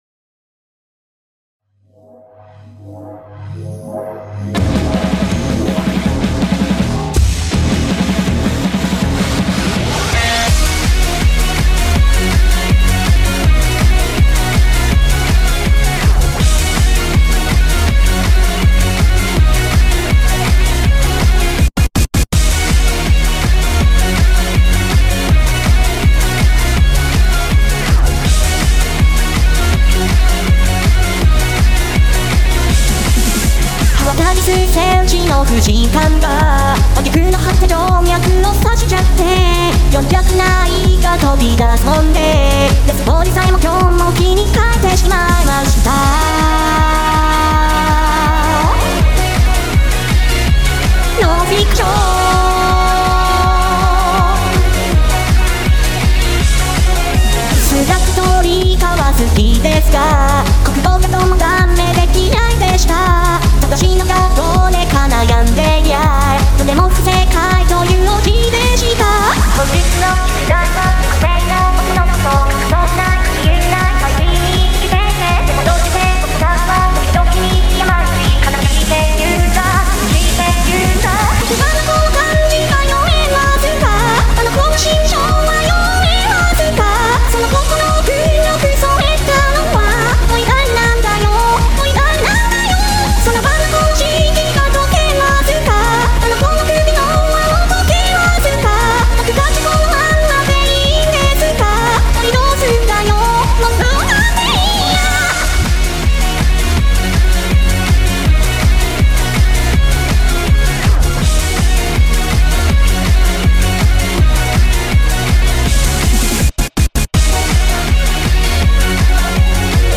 Music / Pop